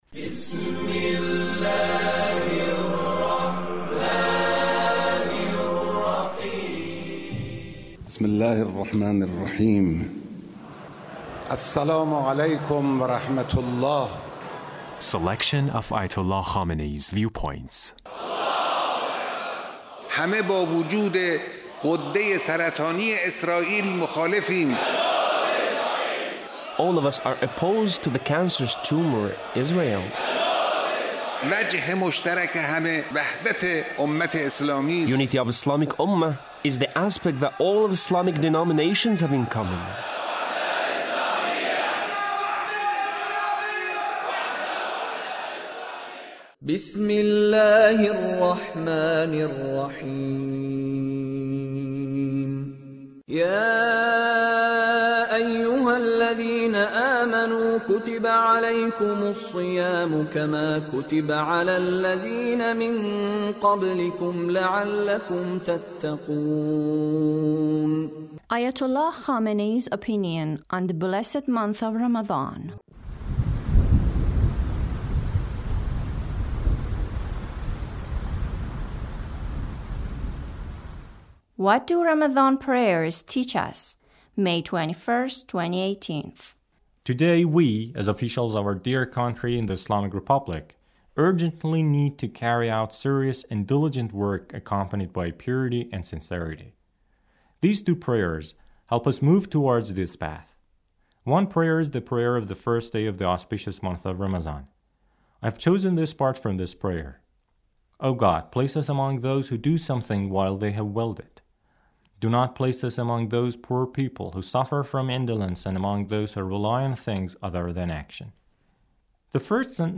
Leader's speech (61)